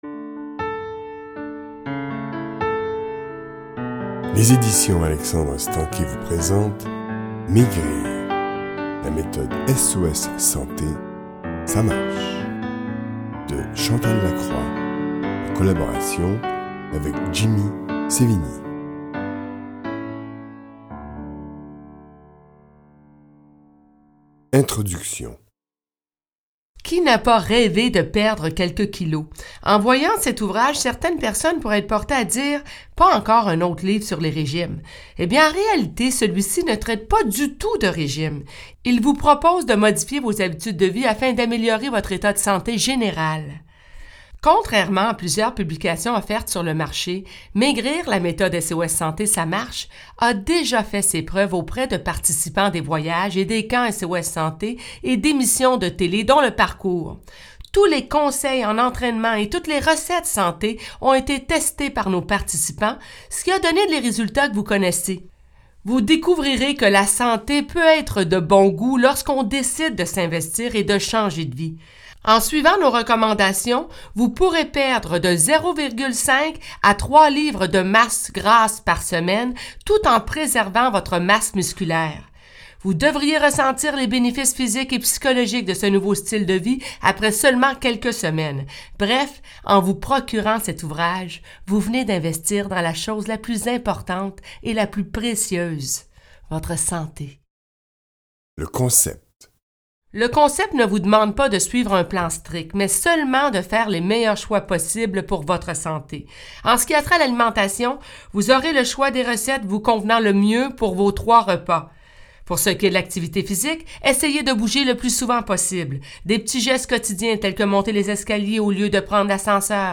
0% Extrait gratuit Maigrir SOS Santé de Chantal Lacroix Éditeur : Coffragants Paru le : 2014 Ce livre audio écrit par Chantal Lacroix en collaboration avec Jimmy Sévigny s’adresse à tous ceux et celles qui désirent perdre du poids de façon saine et efficace. Il vous propose une méthode qui a fait ses preuves depuis plusieurs années.